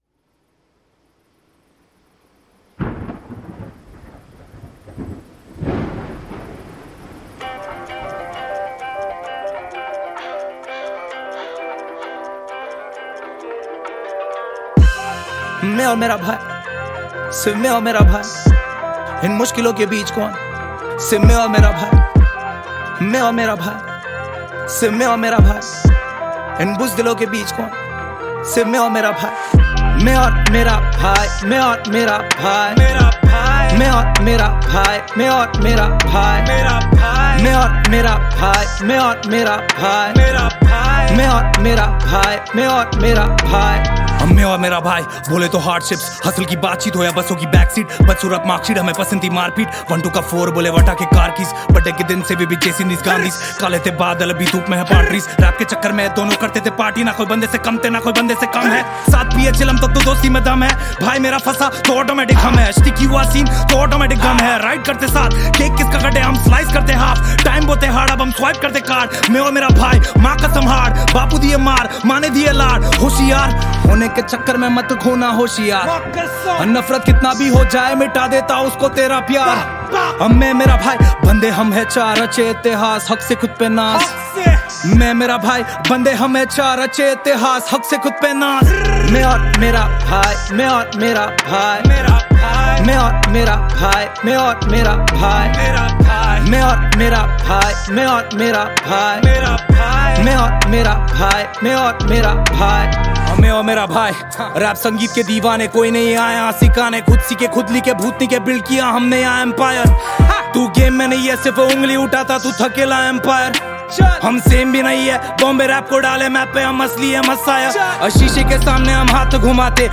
2020 Pop Mp3 Songs